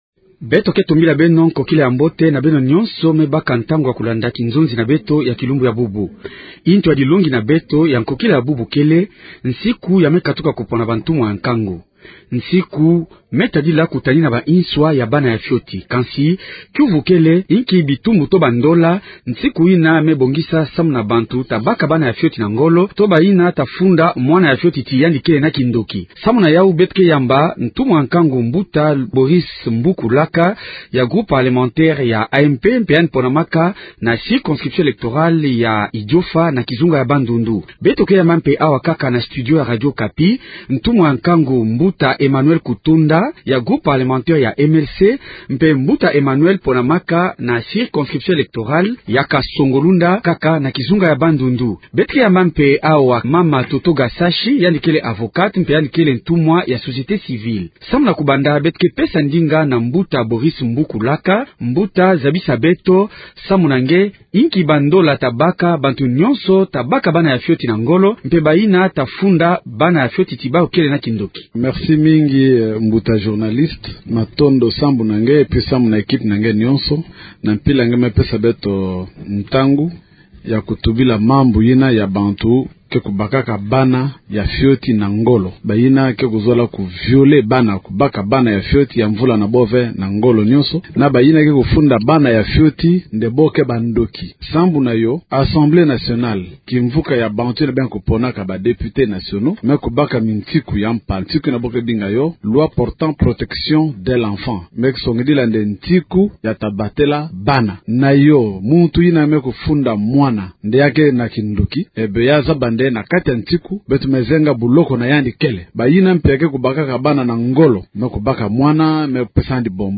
Tel est le thème central du débat en kikongo de ce soir.